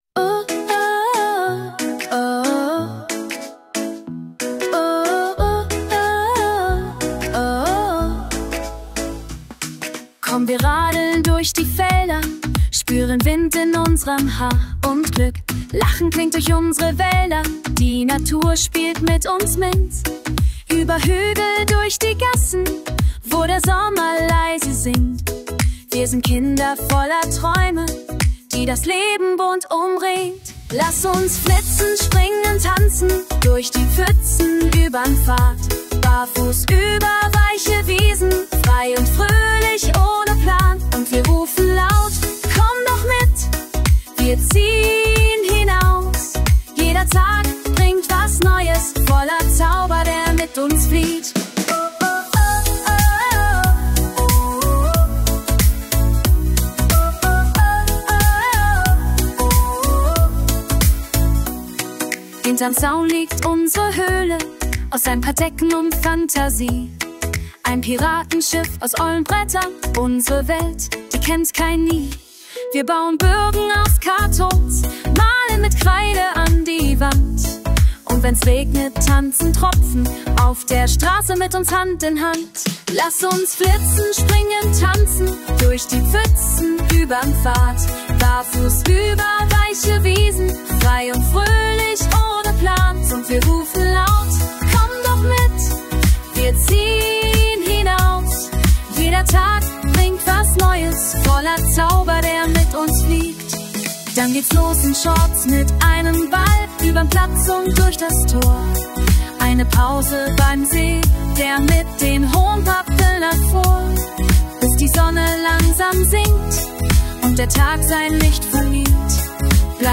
Rock, Metal, Pop und Country